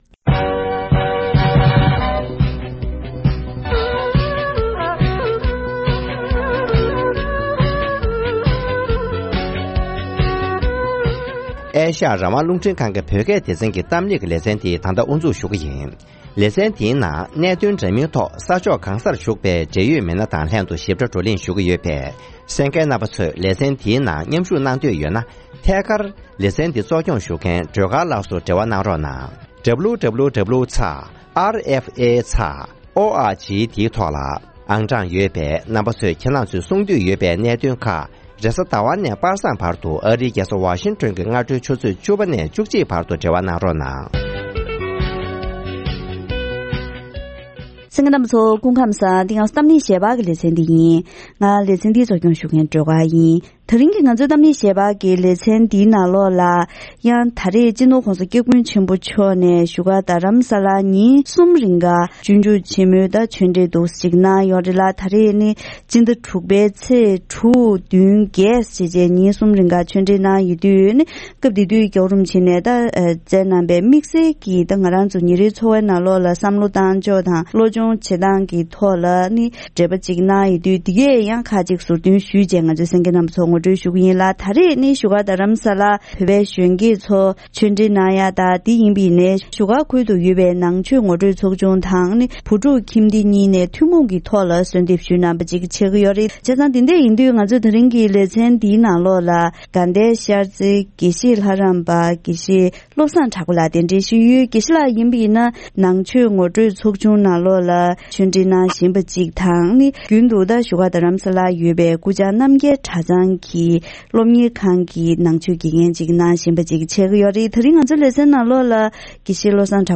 ཉེ་ཆར་༧གོང་ས་མཆོག་ནས་བོད་པའི་གཞོན་སྐྱེས་ཚོར་བཀའ་ཆོས་གནང་སྐབས་ཉིན་རེའི་འཚོ་བ་དང་བསམ་བློ་འཁྱེར་ཕྱོགས་ཐད་བཀའ་སློབ་བསྩལ་གནང་མཛད་ཡོད་པ།